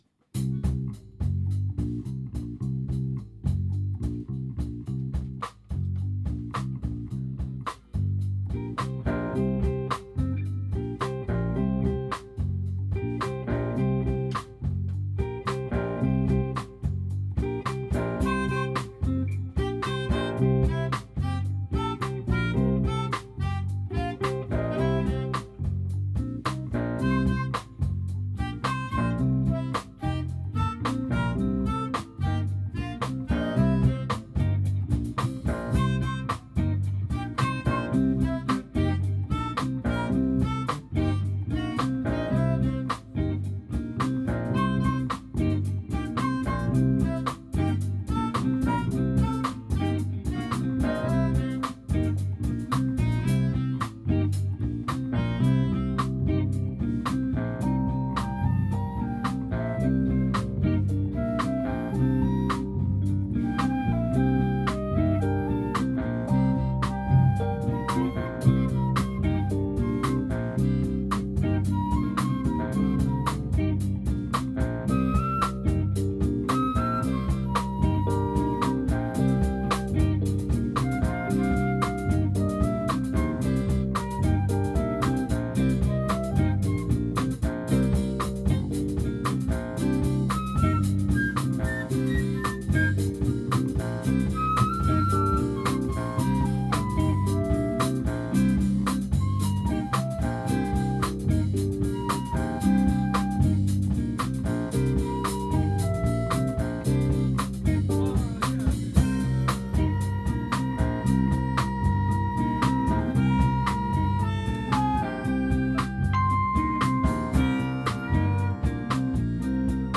Theme de flute assez simple, et solos spectaculaires (ca va vite !)
7/4 (dédouble des 7 note de basse)
Départ Solo flûte Signaux de fumée Départ solo guitare
ensuite Vibraphone  puis THème B  X fois